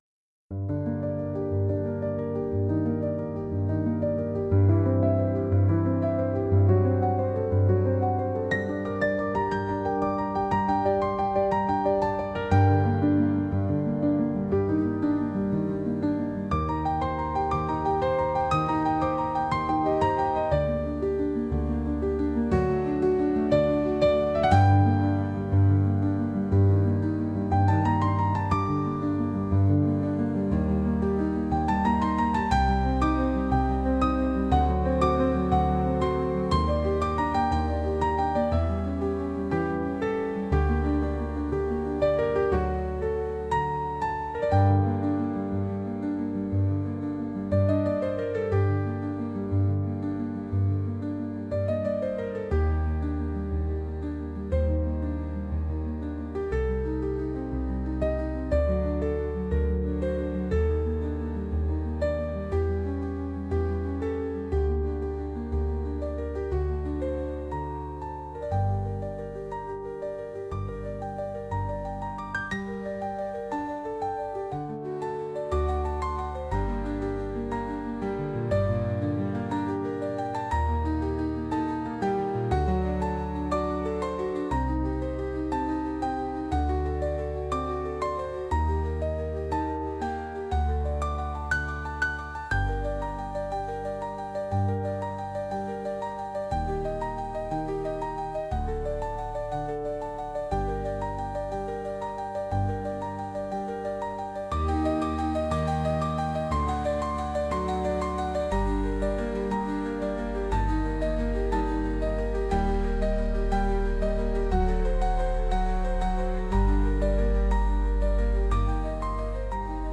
Musica per pianoforte